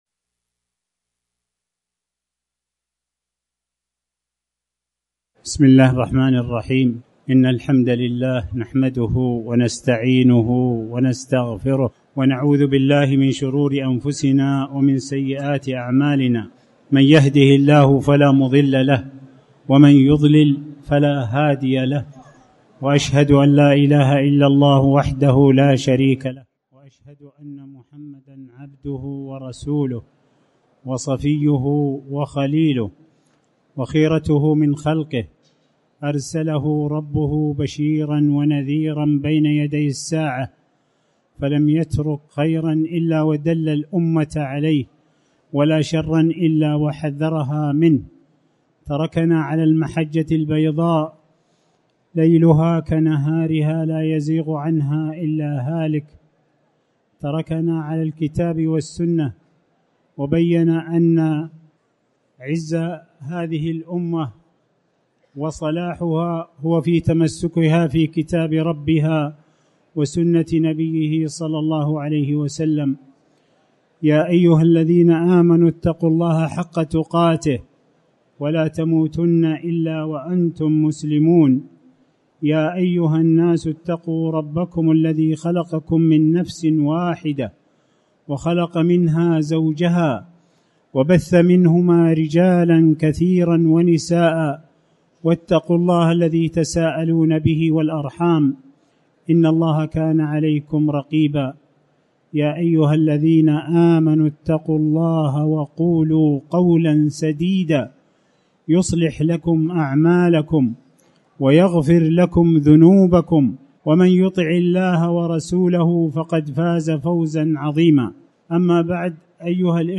المكان: المسجد الحرام
28شعبان-محاضرة-فصل-لربك-ونحر.mp3